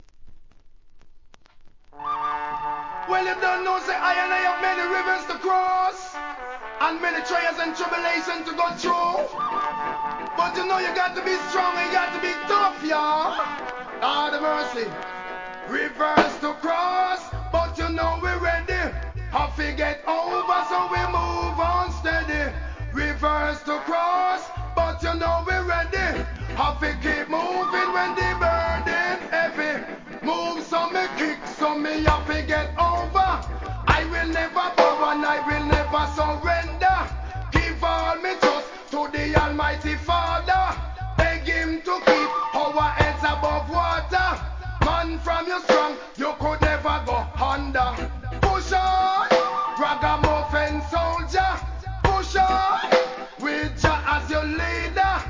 REGGAE
1997年、イントロのフォーンから渋いです!!